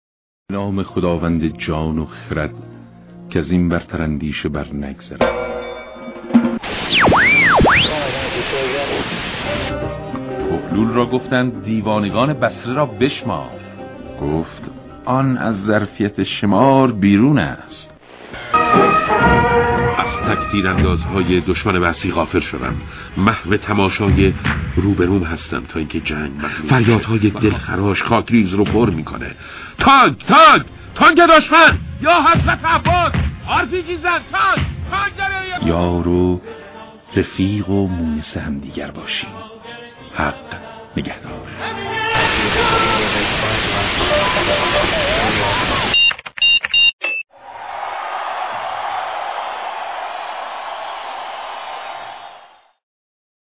این کلیپ کوتاهی از چند اجرای مهران دوستی است. دوستی اجراهای خاطره‌انگیزی در رادیوی ایران داشته است و صدای او برای چند نسل تبدیل به خاطره شده است.